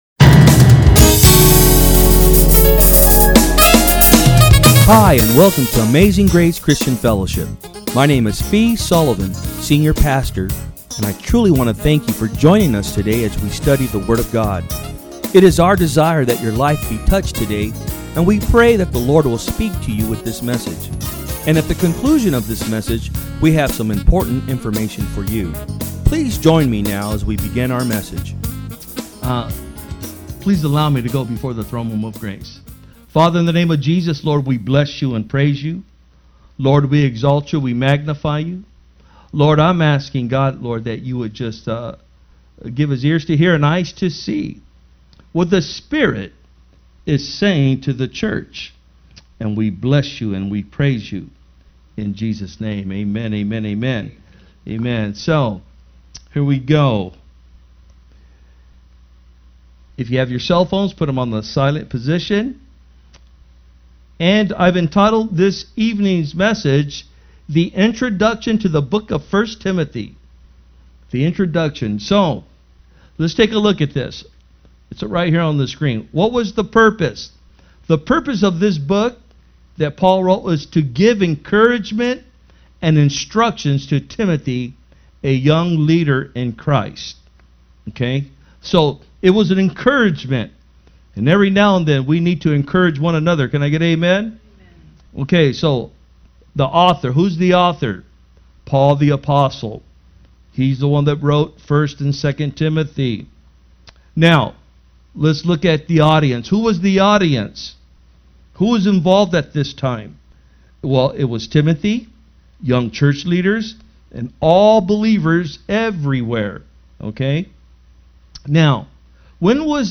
Sermons
From Service: "Wednesday Pm"